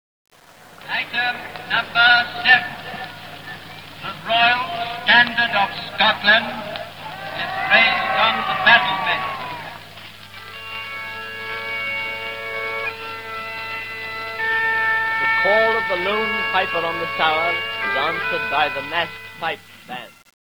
Also, we had a stack of old 78's records, with a recording of the lonely Piper on the Tower, being announced by the words: "The Royal Standard of Scotland is raised on the Battlement; the call of the Lonely Piper is answered by the massed pipe band."
It was only many years later that I discovered what I  had heard was Piobaireachd, in fact part of the beginning of the Chattan's Salute. Those magical sounding few notes kept haunting me and I just had to find out more, urgently wanting to be able to play this fantastic instrument myself too.